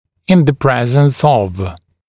[ɪn ðə ‘prezns ɔv][ин зэ ‘прэзнс ов]в присутствии